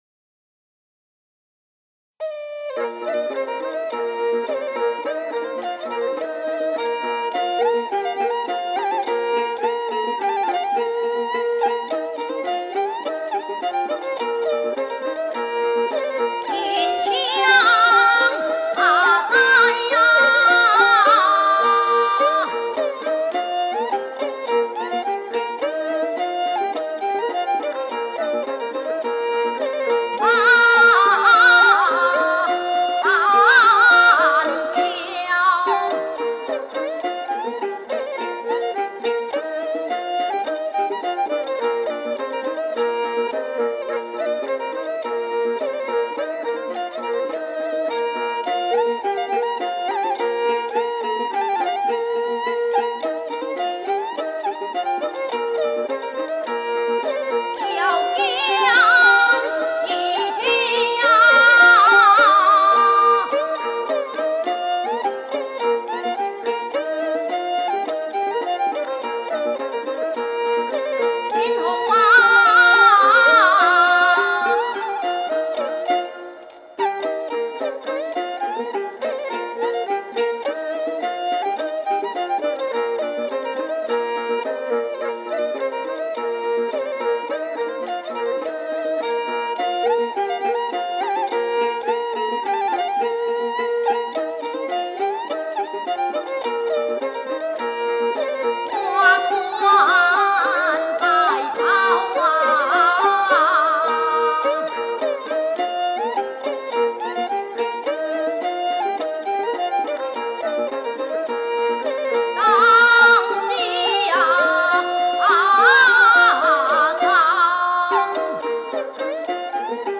福路戲曲唱腔【十二丈】 | 新北市客家文化典藏資料庫